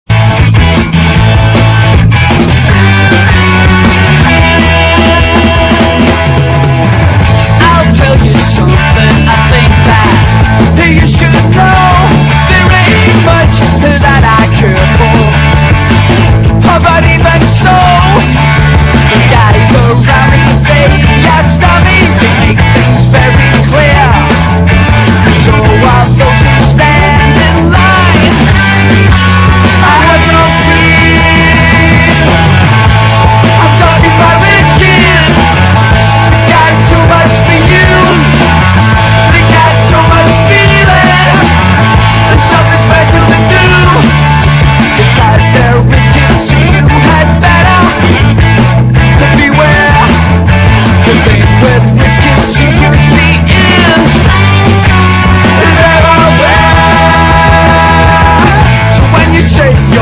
EASY LISTENING / EASY LISTENING / SUNSHINE POP
時にジャジー、時にファンキーと、ヴァラエティーに富んだ曲調＆アレンジが素晴らしい全く侮れないマイナー盤です！